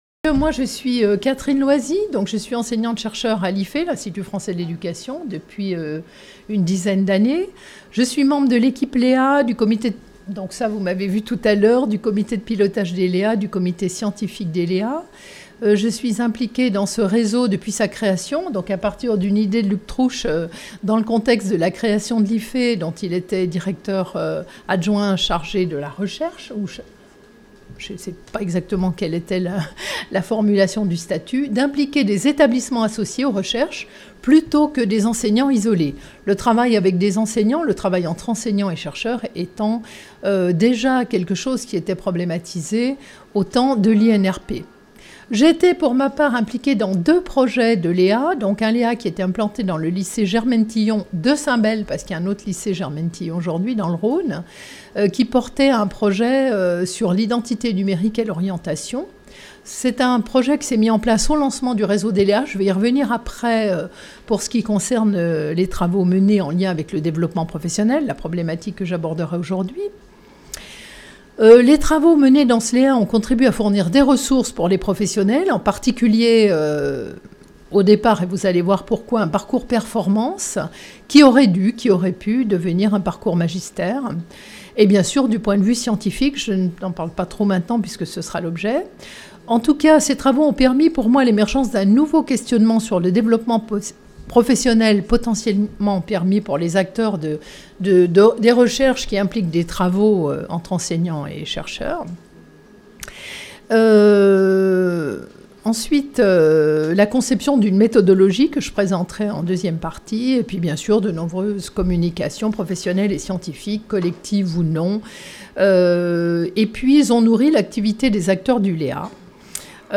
Le séminaire de rentrée a eu lieu le mercredi 13 novembre 2019 à l'IFÉ.